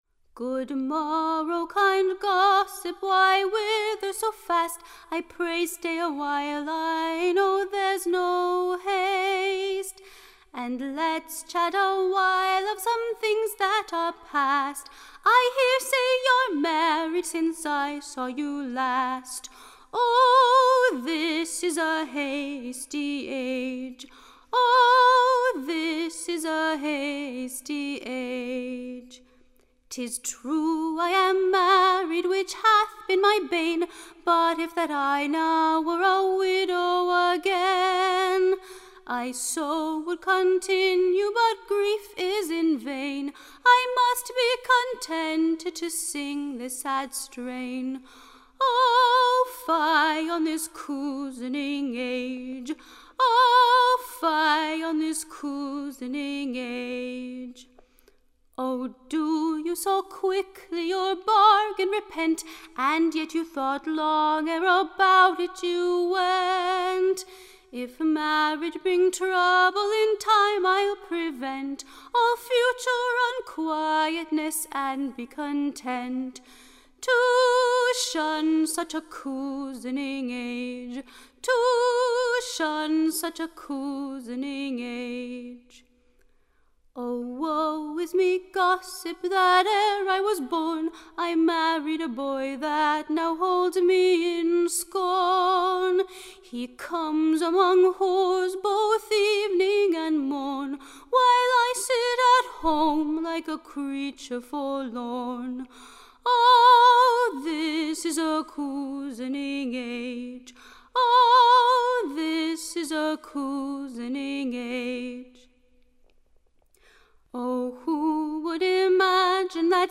Recording Information Ballad Title The cunning Age.